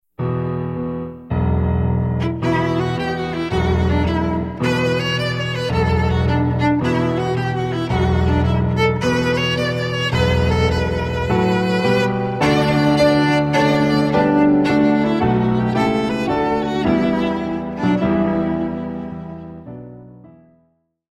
violin duet and lever or pedal harp (or piano)
Christmas sheet music for 2 violins and harp or piano